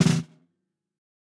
TUNA_SNARE_1.wav